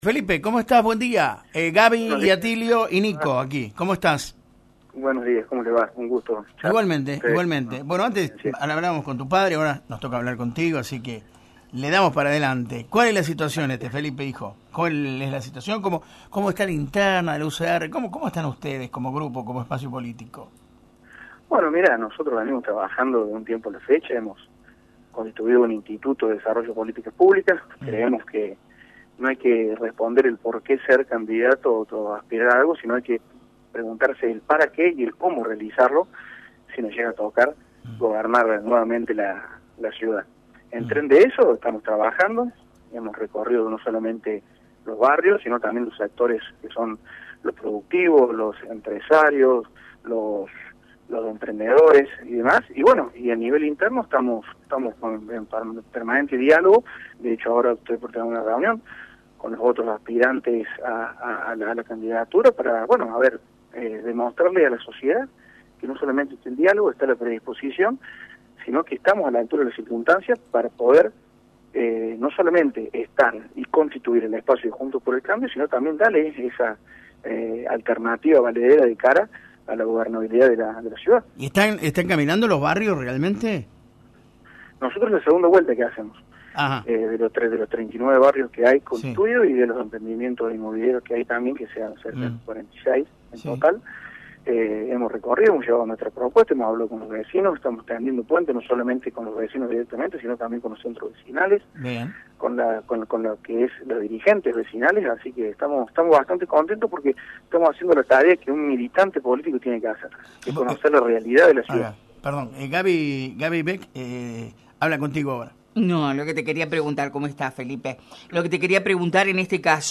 Este viernes, dialogamos en «La Mañana Informal»